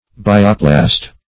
Search Result for " bioplast" : The Collaborative International Dictionary of English v.0.48: Bioplast \Bi"o*plast\ (-pl[a^]st), n. [Gr. bi`os life + pla`ssein to form.]